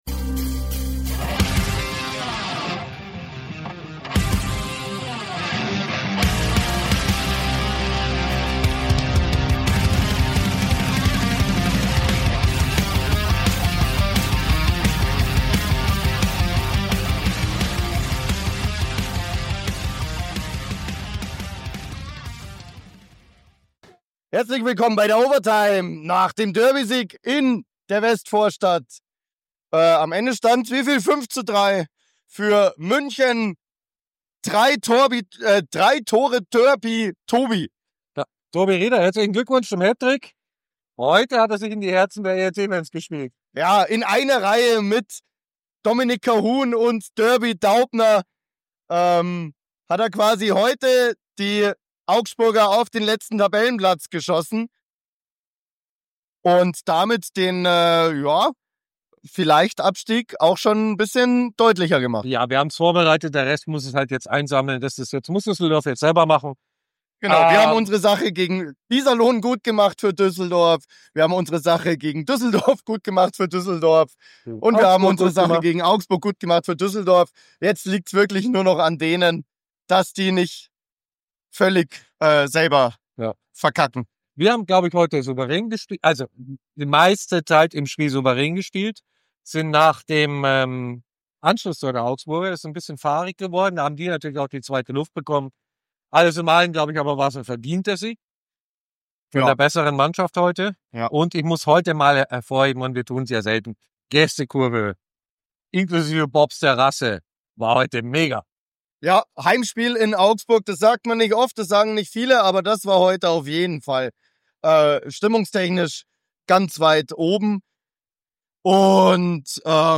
Spielzusammenfassung und Stimmen
Intro und Outro sind von der Band Viper Queen!